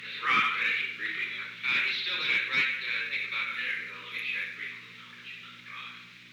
Secret White House Tapes
Conversation No. 917-30
Location: Oval Office
The President met with an unknown man.